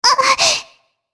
Shea-Vox_Damage_jp_02.wav